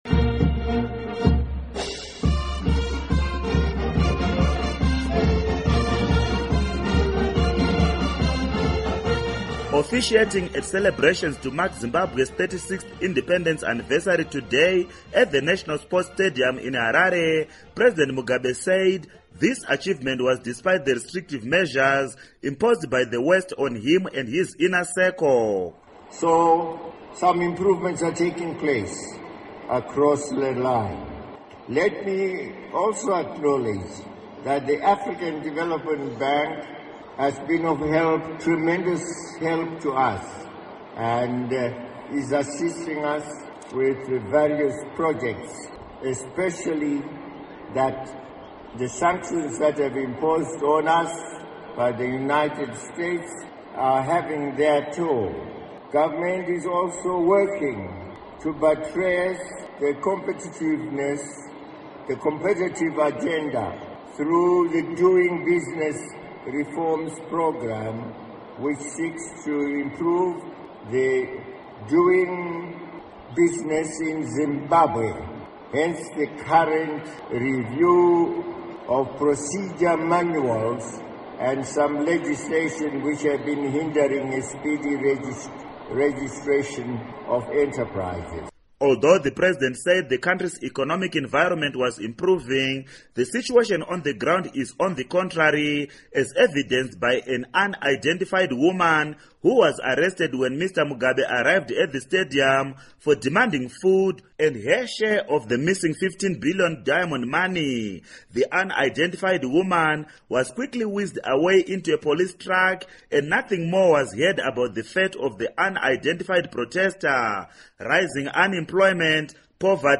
Report on Zimbabwe Independence